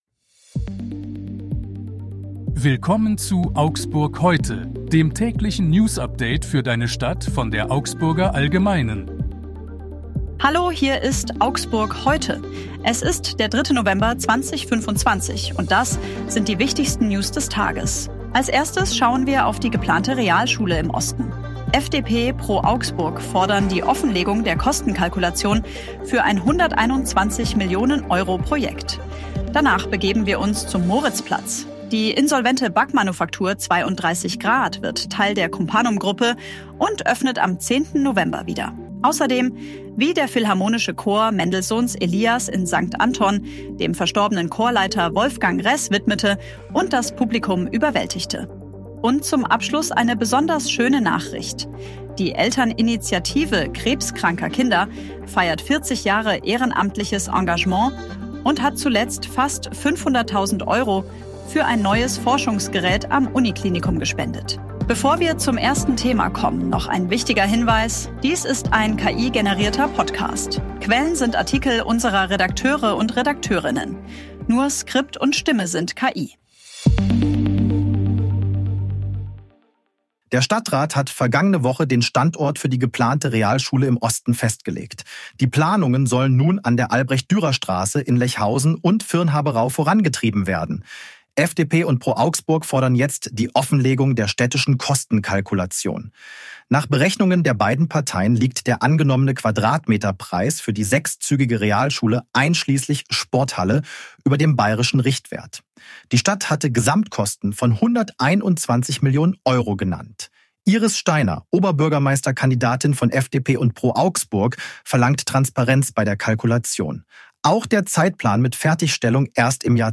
Dies ist ein KI-generierter Podcast.
Nur Skript und Stimme sind KI.